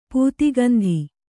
♪ pūti gandhi